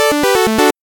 notify1.ogg